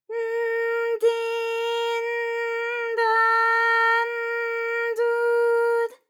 ALYS-DB-001-JPN - First Japanese UTAU vocal library of ALYS.
d_n_di_n_da_n_du_d.wav